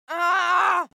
دانلود آهنگ دعوا 25 از افکت صوتی انسان و موجودات زنده
دانلود صدای دعوا 25 از ساعد نیوز با لینک مستقیم و کیفیت بالا
جلوه های صوتی